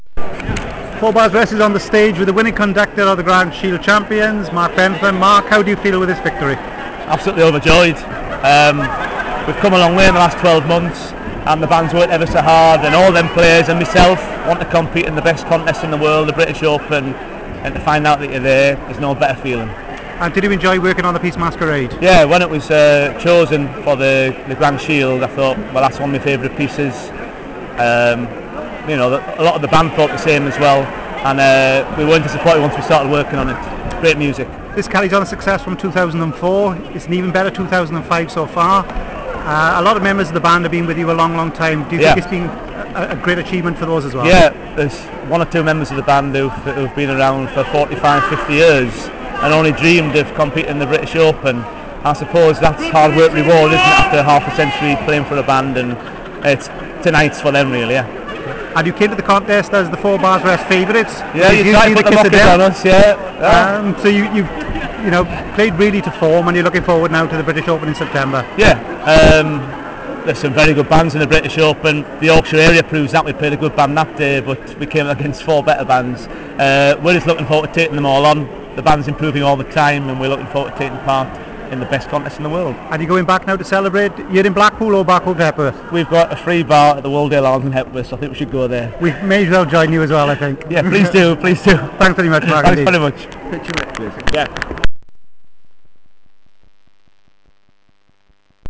Audio interviews: